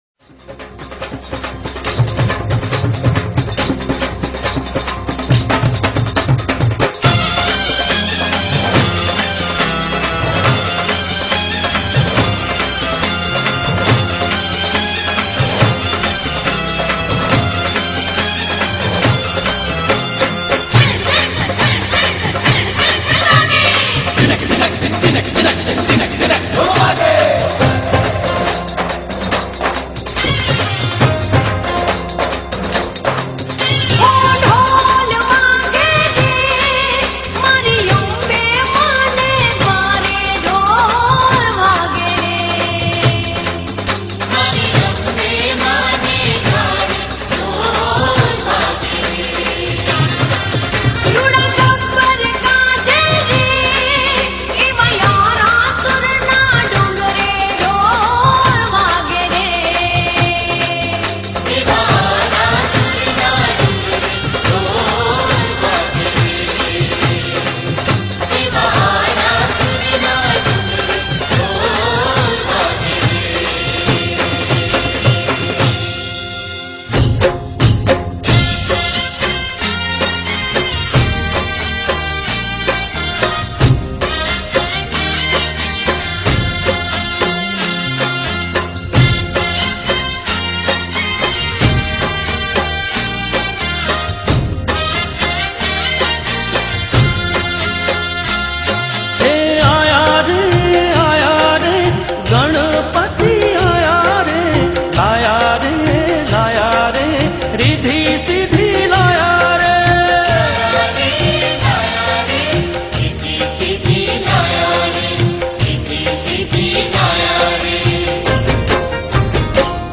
Non Stop Dandiya Mix Track